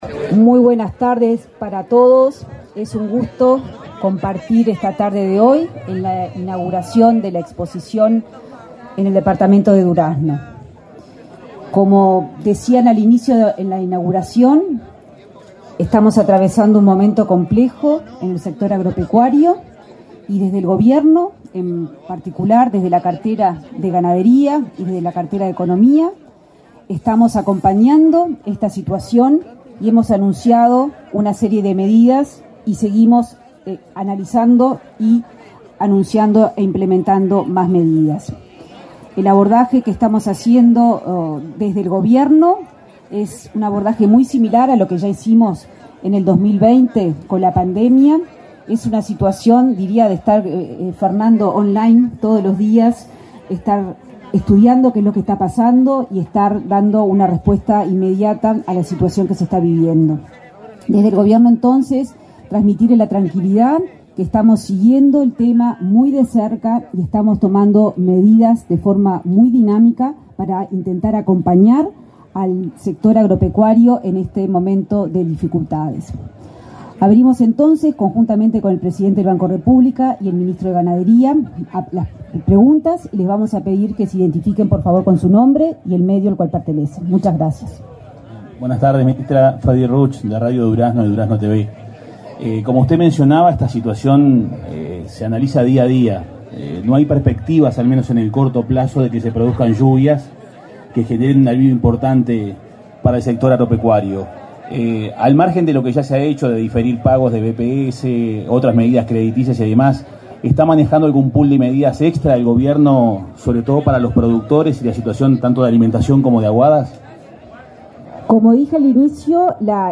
Conferencia de las autoridades del Gobierno en Expo Durazno
Conferencia de las autoridades del Gobierno en Expo Durazno 23/02/2023 Compartir Facebook Twitter Copiar enlace WhatsApp LinkedIn Con motivo de la inauguración de la Expo Durazno 2023, este 23 de febrero, la ministra de Economía y Finanzas, Azucena Arbeleche; el ministro de Ganadería, Agricultura y Pesca, Fernando Mattos, y el presidente del Banco República, Salvador Ferrer, se expresaron en conferencia de prensa.